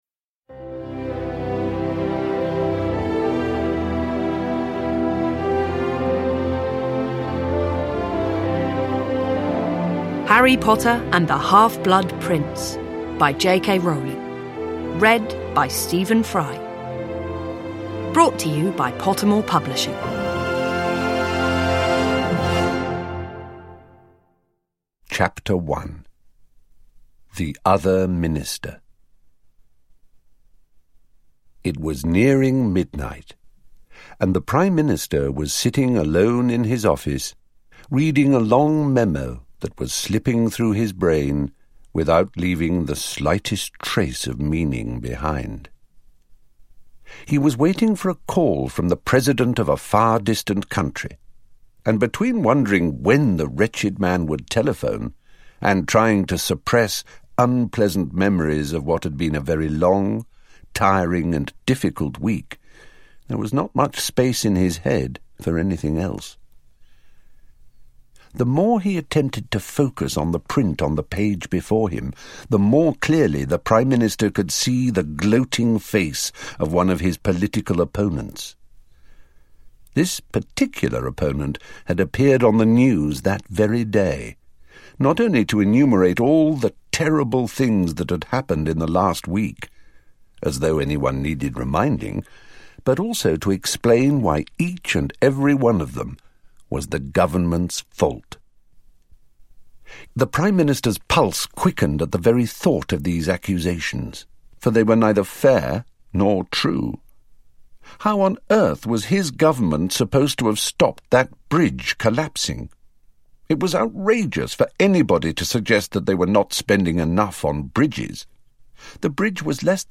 Harry Potter and the Half-Blood Prince (ljudbok) av J.K. Rowling